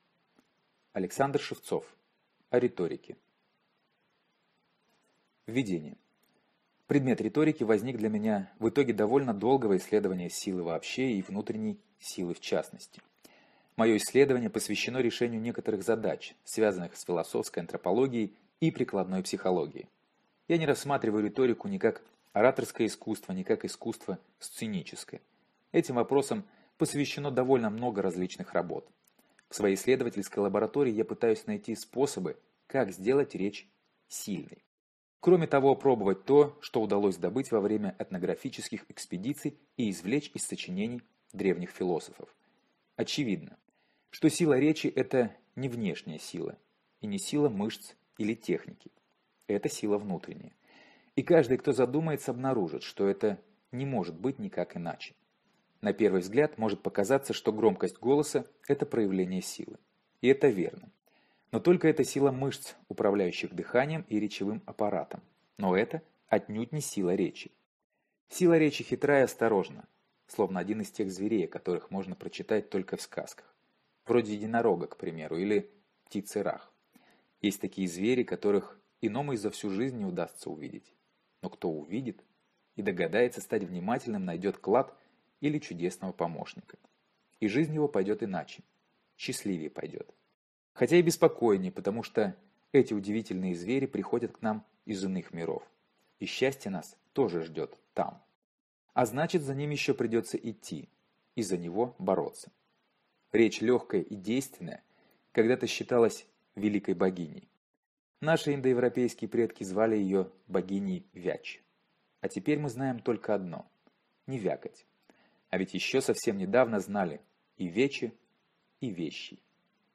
Аудиокнига О риторике | Библиотека аудиокниг